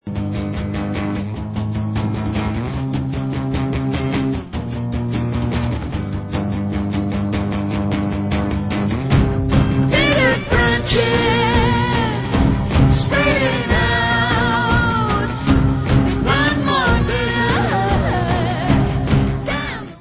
sledovat novinky v oddělení Alternative Rock